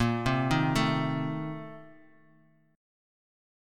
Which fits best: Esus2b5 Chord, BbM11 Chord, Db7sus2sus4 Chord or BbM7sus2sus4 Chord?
BbM7sus2sus4 Chord